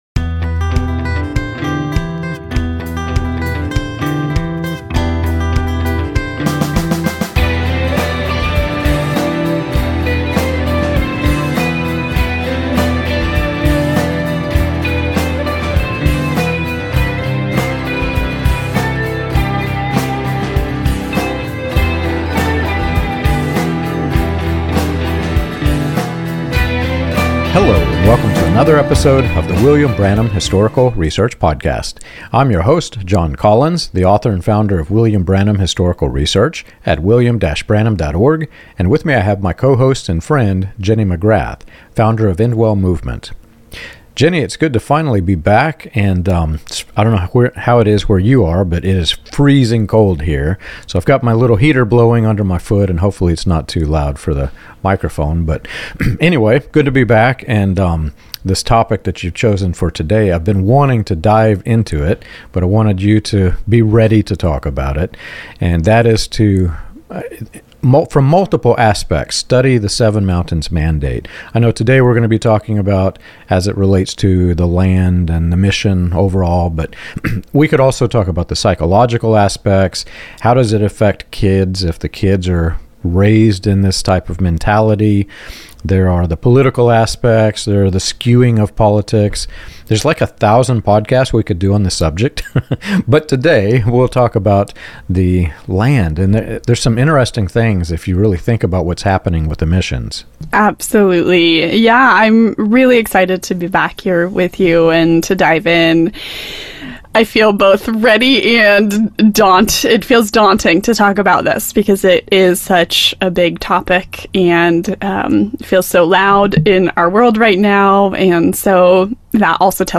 The conversation traces how business models, donor funding, short-term mission programs, and global networks intersect with dominion theology, raising hard questions about exploitation, transparency, and the real cost to communities on the ground. Rather than abstract theology, the discussion exposes how ideas translate into property, influence, and control across nations.